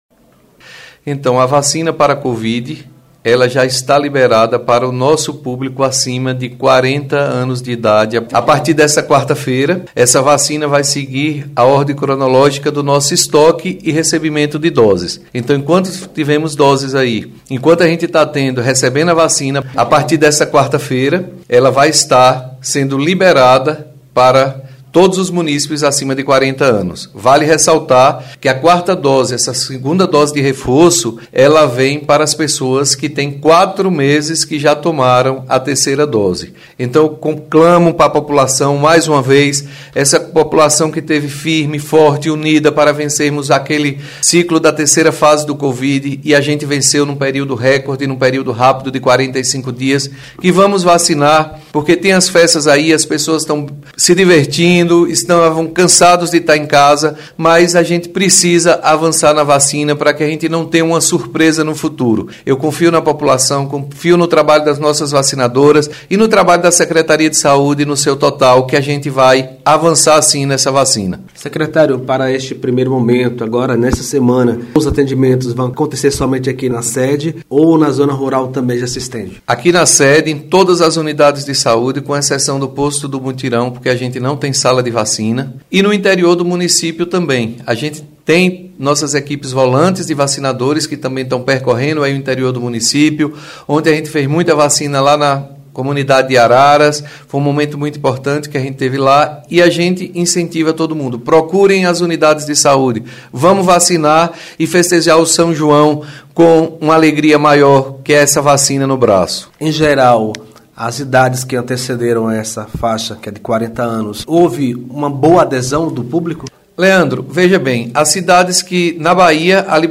Secretário de saúde Paulo Henrique – 4ª dose da vacina contra a Covid